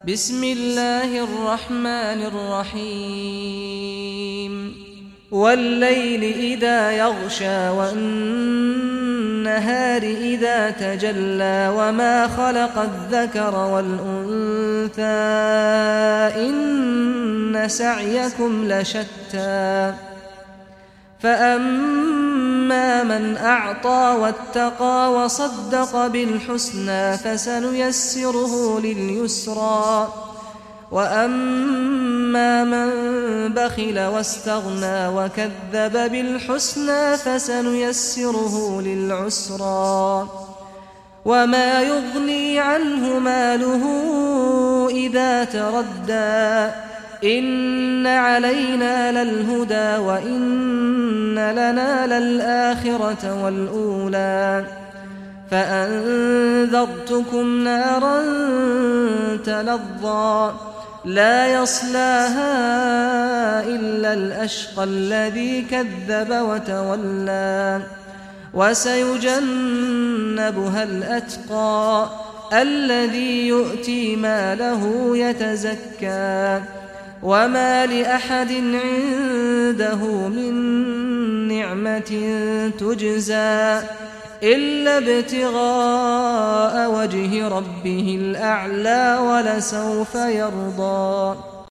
Surah Al-Layl Recitation by Sheikh Saad al Ghamdi
Surah Al-Layl, listen or play online mp3 tilawat / recitation in Arabic in the beautiful voice of Sheikh Saad al Ghamdi.